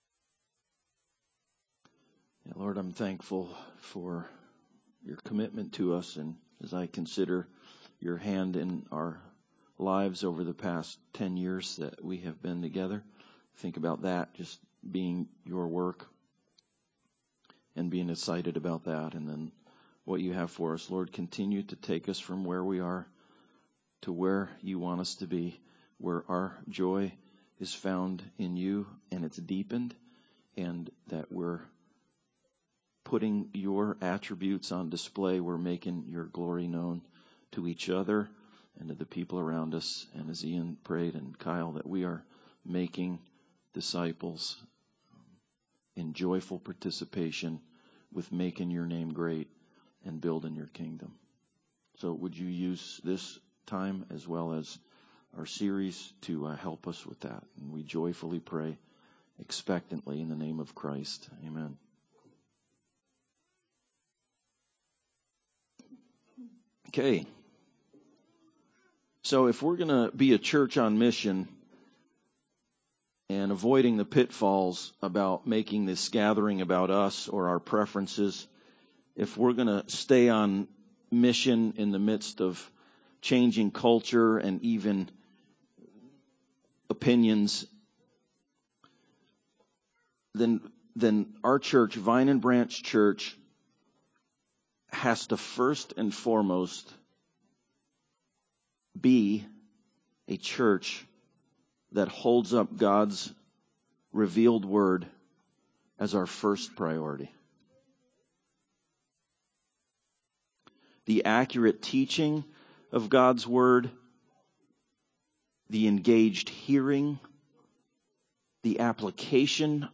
On Mission Service Type: Sunday Service Preacher